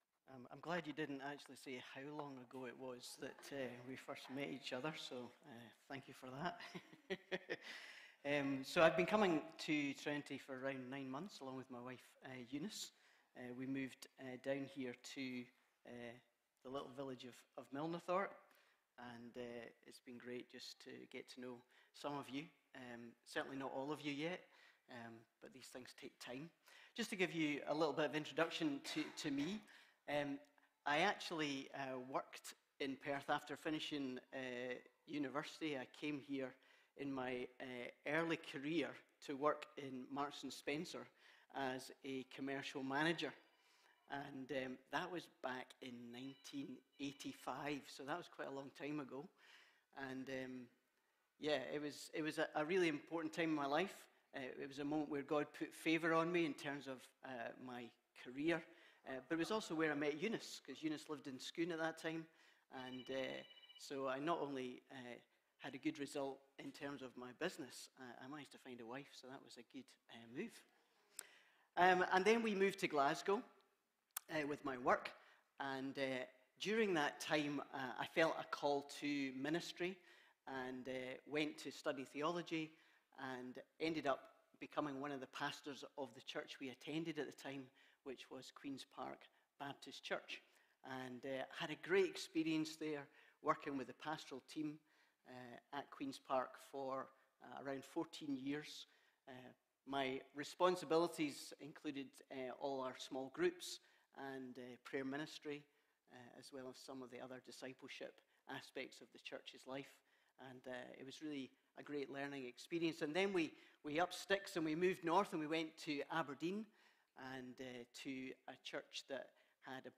Sermons | Trinity Church of the Nazarene
Guest Speaker